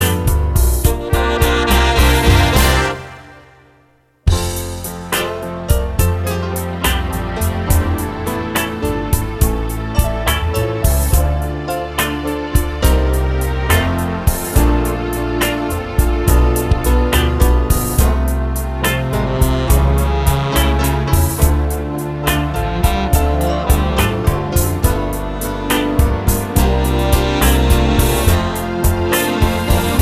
Two Semitones Down Jazz / Swing 4:18 Buy £1.50